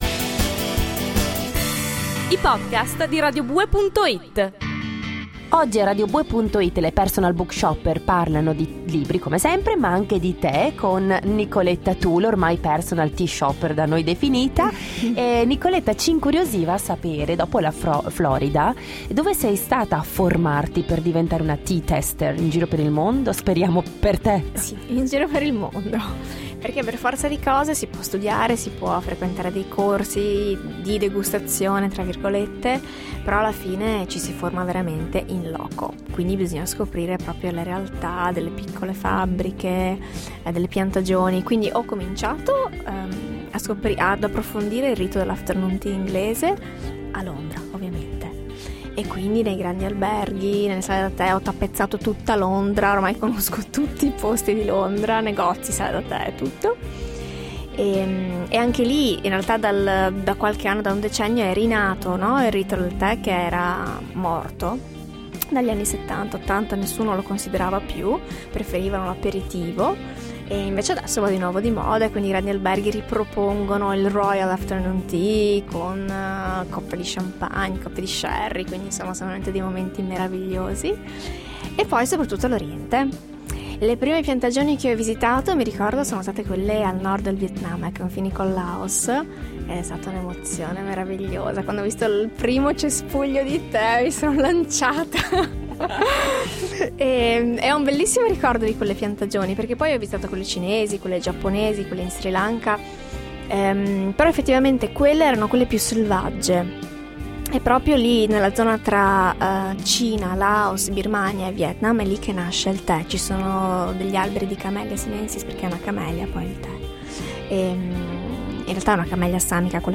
Ecco la radio recensione